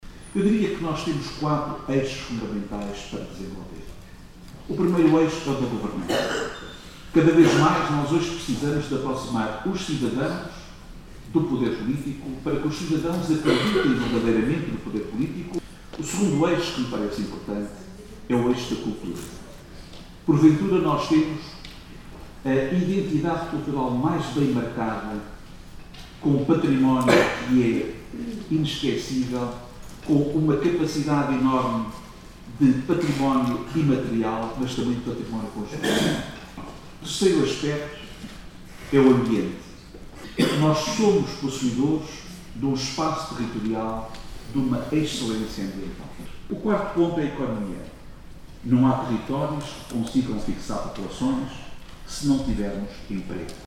jose-maria-forum.mp3